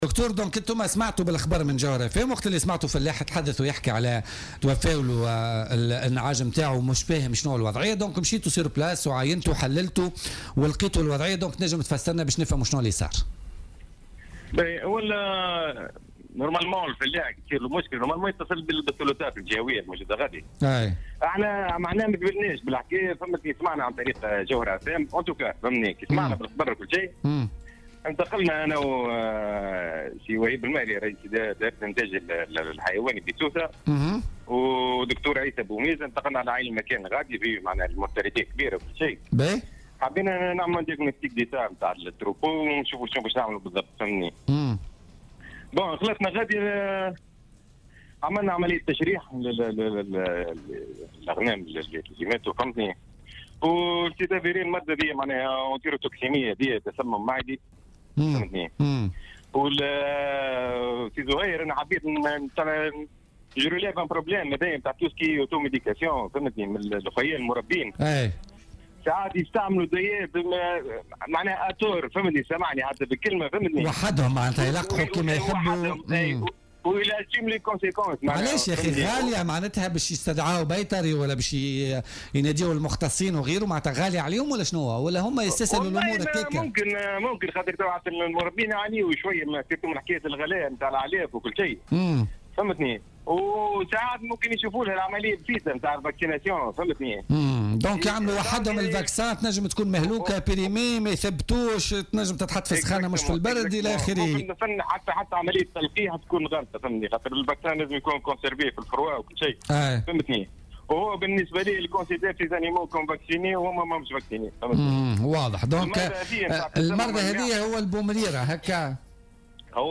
في اتصال هاتفي بـ"الجوهرة أف أم"